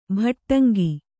♪ bhaṭṭangi